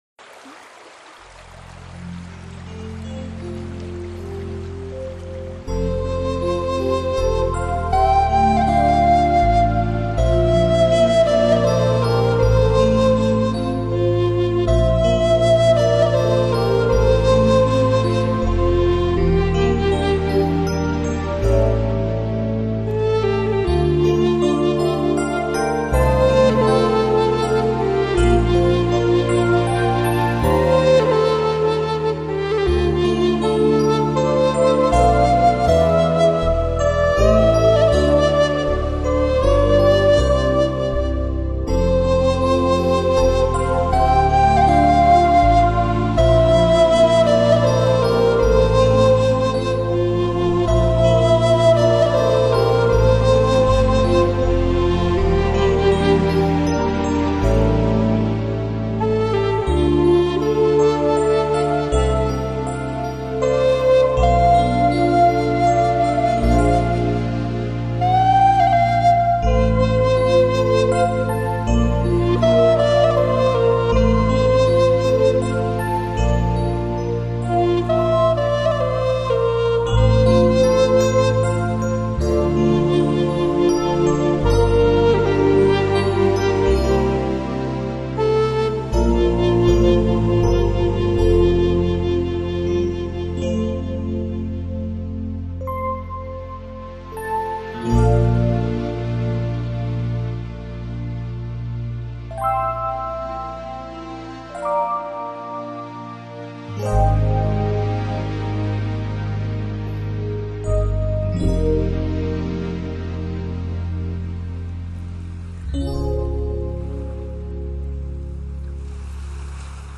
音质而风靡全国，独具一格的空灵乐风，让聆听者随之倾倒。
音乐，舒适的听觉享受，让紧绷的身心，完全释放……这音乐